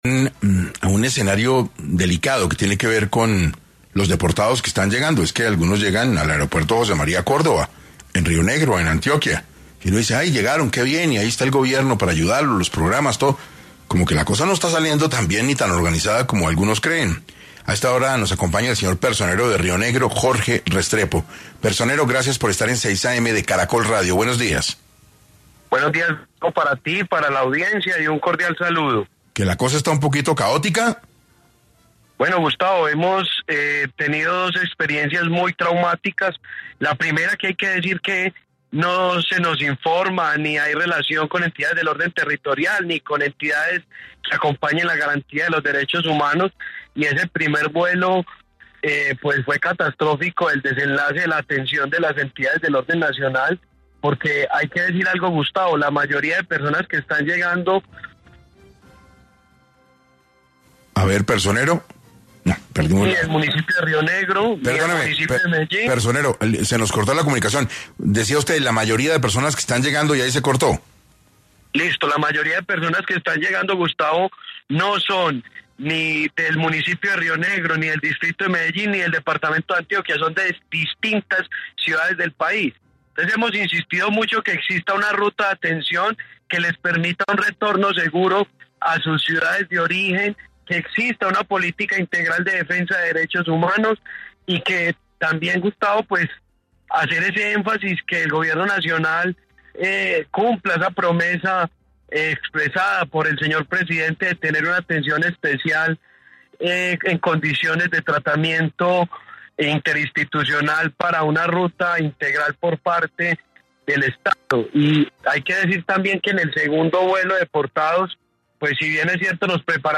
En 6AM estuvo Jorge Restrepo, personero de Rionegro, Antioquia, se refirió a las condiciones en las que han hecho tránsito los deportados en el aeropuerto José María Córdova